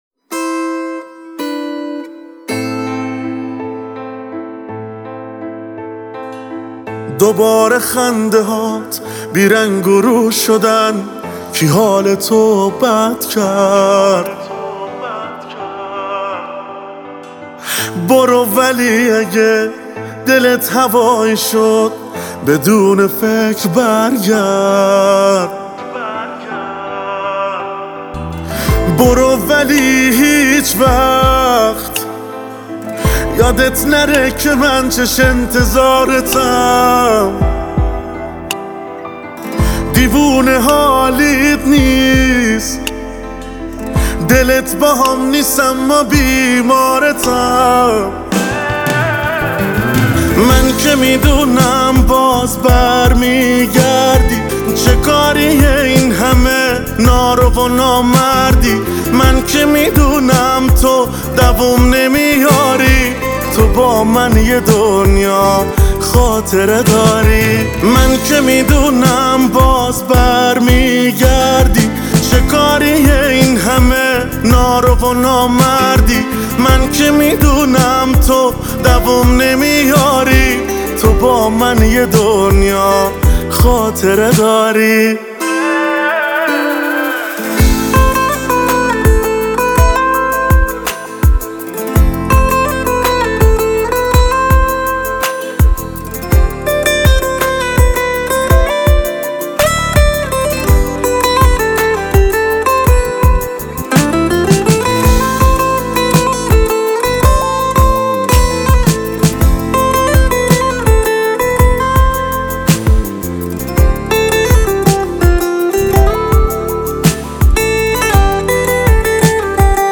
موزیک شنیدنی و احساسی